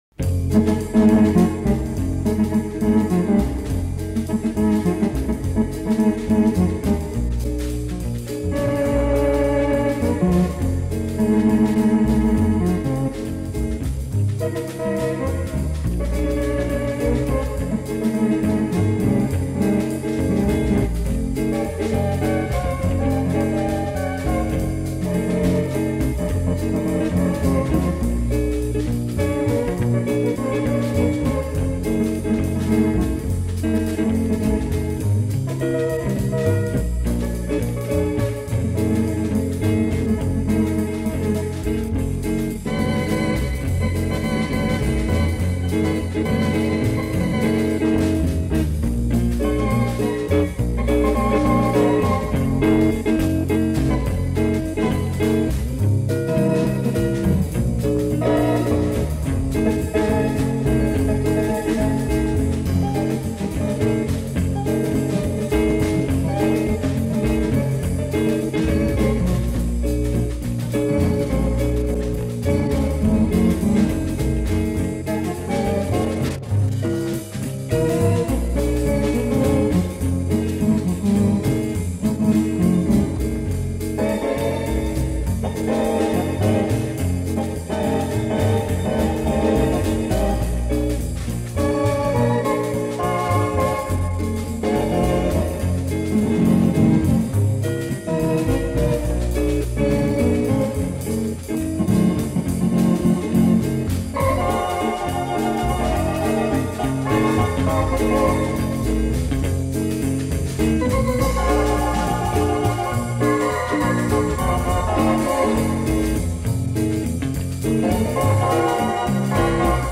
jazzy easy listening